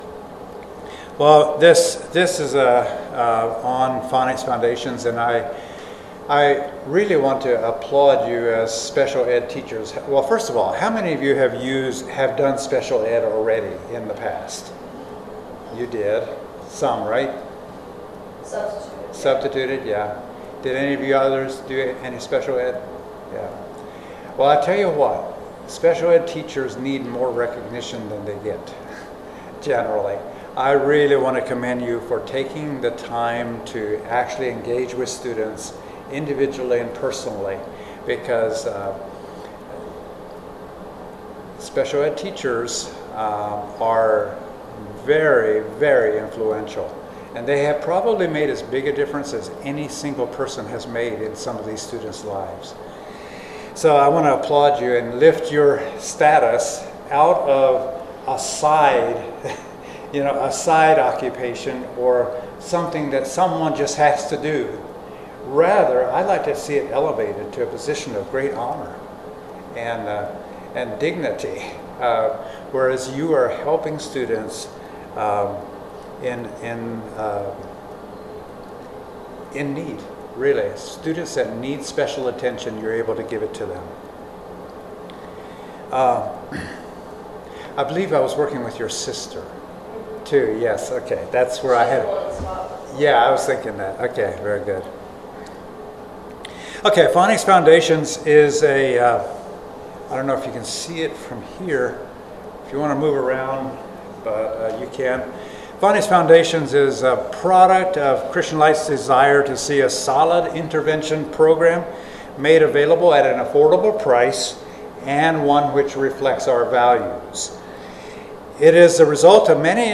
Midwest Teachers Week 2025 Recordings